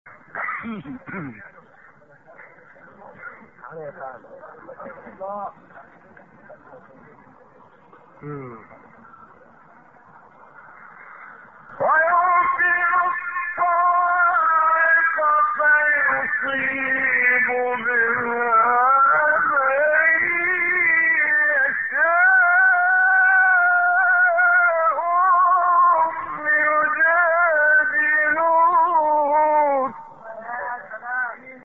گروه فعالیت‌های قرآنی: فرازهایی در مقام صبا با صوت محمد عمران ارائه می‌شود.
برچسب ها: خبرگزاری قرآن ، ایکنا ، فعالیت های قرآنی ، مقام صبا ، محمد عمران ، قاری مصری ، فراز صوتی ، نغمه ، قرآن ، iqna